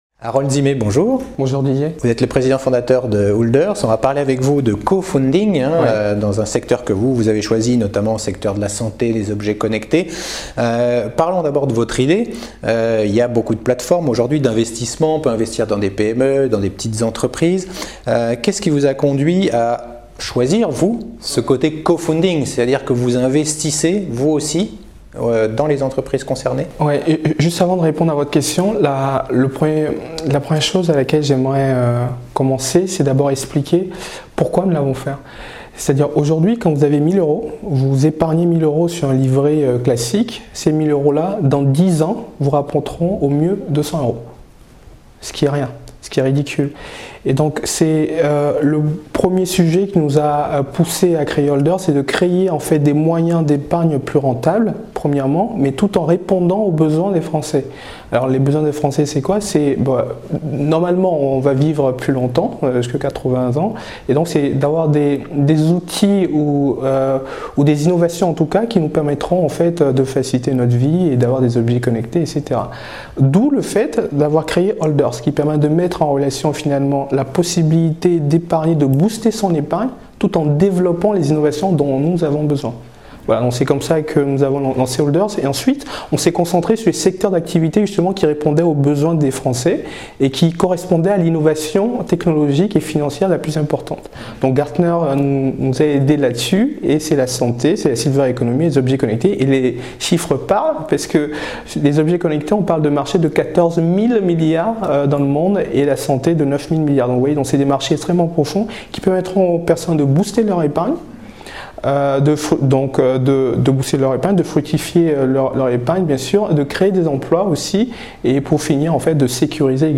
J’en parle avec mon invité